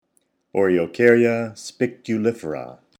Pronunciation/Pronunciación:
O-re-o-cár-ya spiculifera